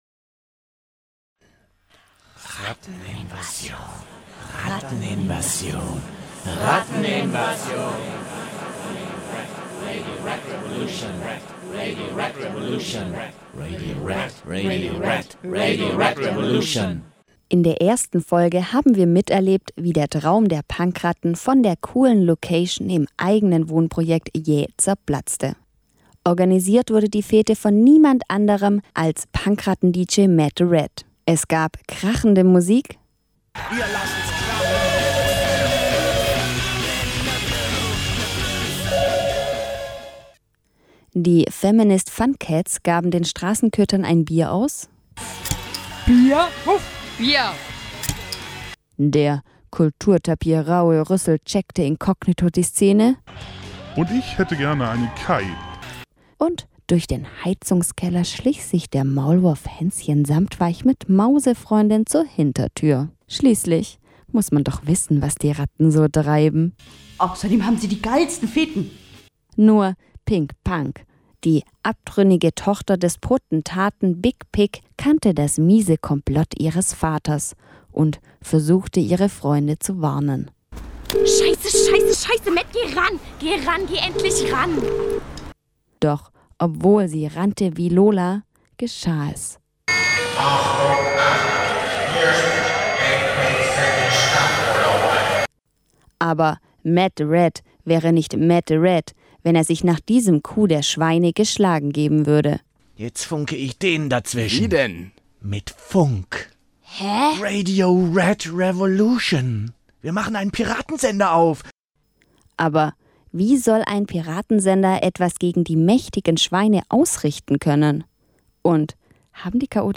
Freies Radio Wüste Welle - Hörspiele aus der Werkstatt - Radio Rat Revolution: Pilotsendung und 1.